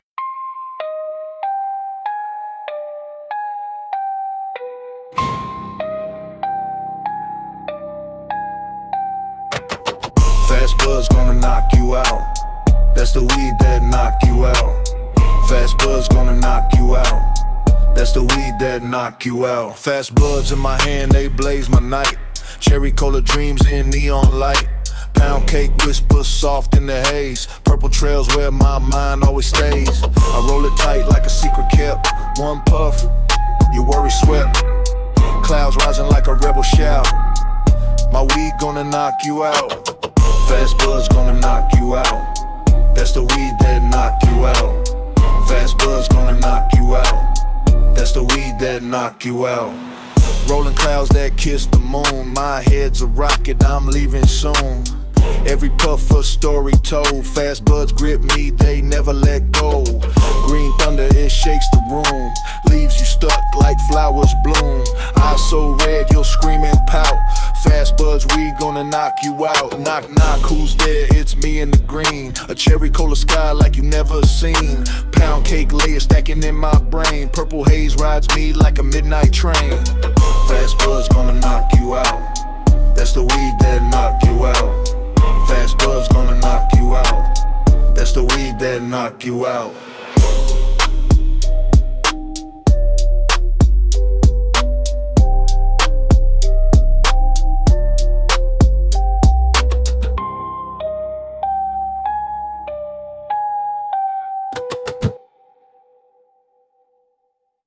rap 17 Dec 2025